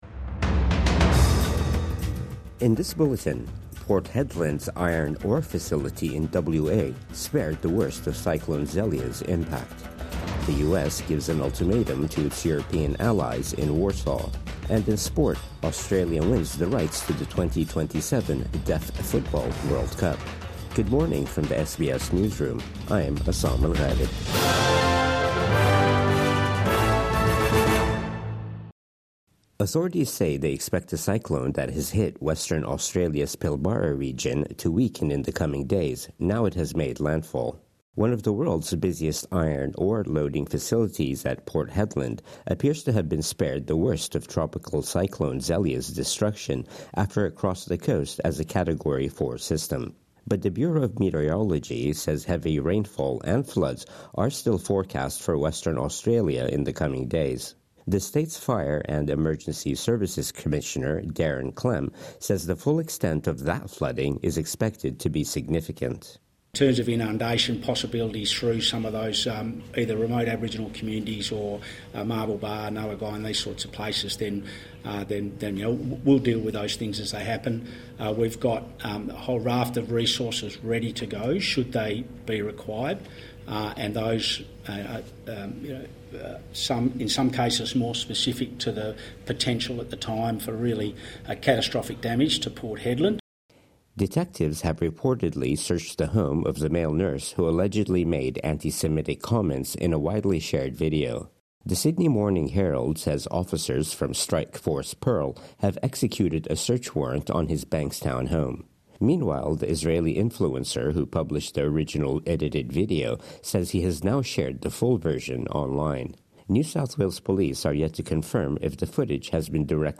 Morning News Bulletin 15 February 2025